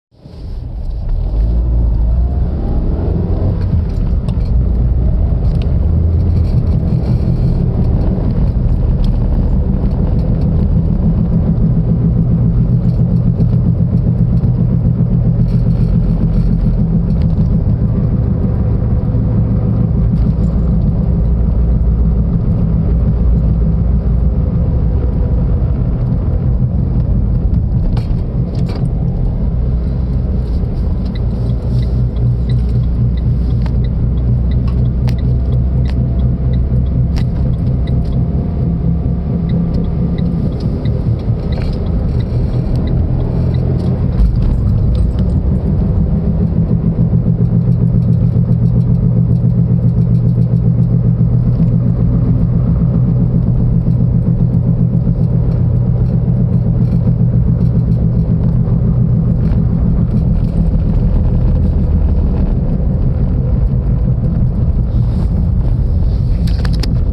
Renault Twingo 2010: Kloppend geluid bij toenemen snelheid
Bij toenemende snelheid, vanaf 30 km/u, ontstaat (voor het gevoel) rechtsvoor een kloppend geluid, alsof er iemand op de onderkant van de voetenbak klopt. De frequentie is afhankelijk van de snelheid waarmee gereden wordt, en onafhankelijk van de versnelling en het toerental.
In de bijgevoegde geluidsopname is het het beste te horen na de tweede keer dat de richtingaanwijzer gebruikt wordt, ik trek vanaf een rotonde op naar ca 50 km/u in een flauwe bocht naar links. In het echt klinkt het geluid een stuk harder en zwaarder dan op de opname.